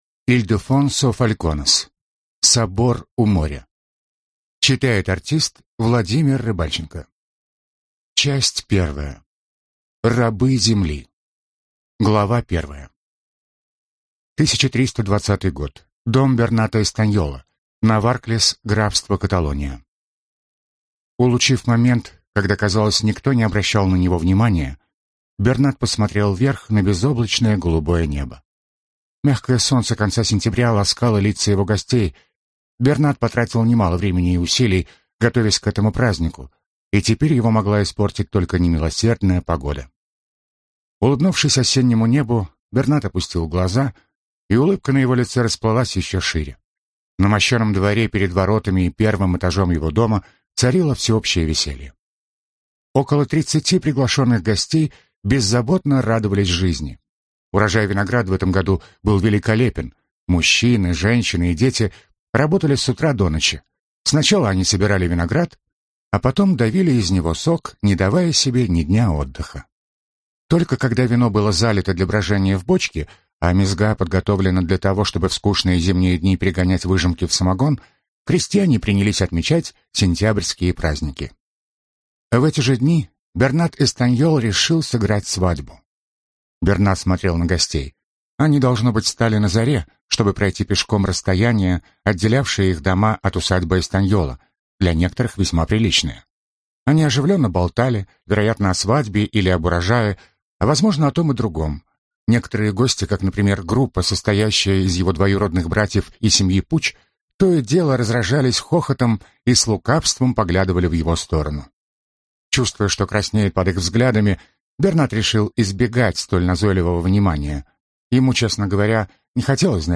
Аудиокнига Собор у моря | Библиотека аудиокниг